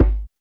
PERC.9.NEPT.wav